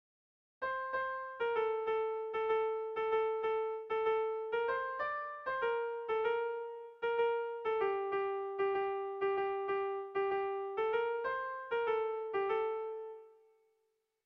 Gabonetakoa
Lauko handia (hg) / Bi puntuko handia (ip)
AB